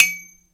Category ⚡ Sound Effects
ding hit metal ring ting tone sound effect free sound royalty free Sound Effects